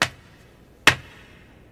Teacher's Footsteps.wav